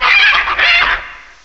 cry_not_mandibuzz.aif